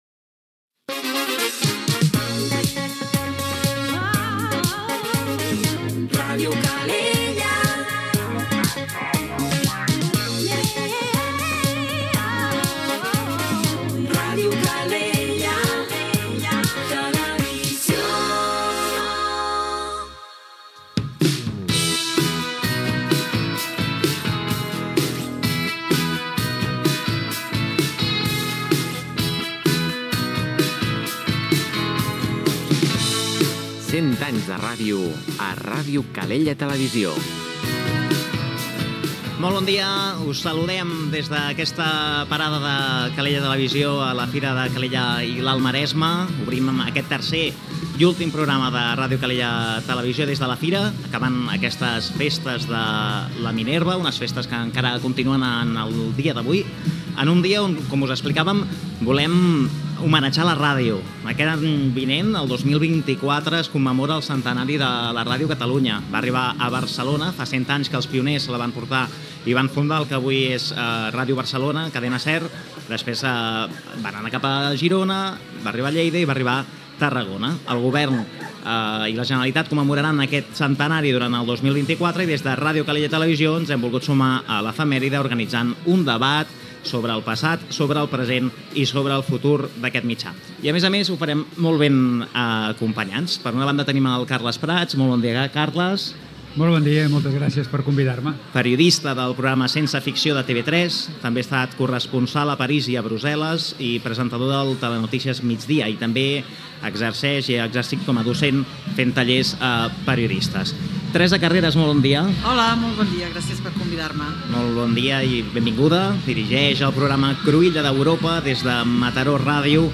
Primera emissora de ràdio que va organitzar un programa especial, cara al públic, dedicat al centenari de la Ràdio a Catalunya